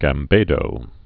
(găm-bādō)